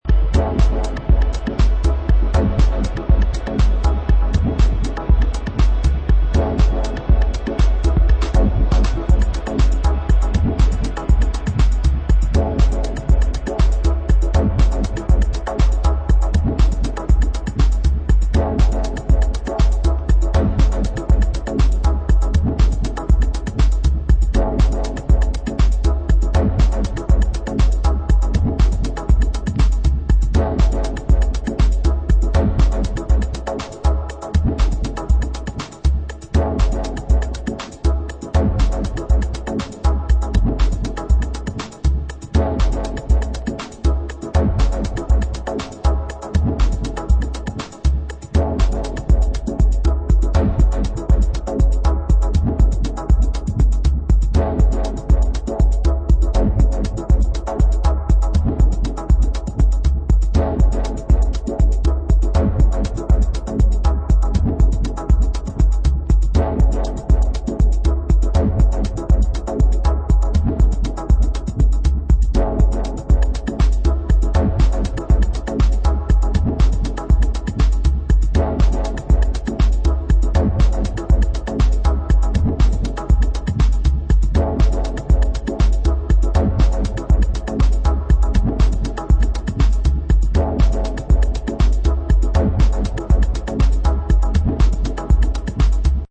Three cuts of deepest party music
Techno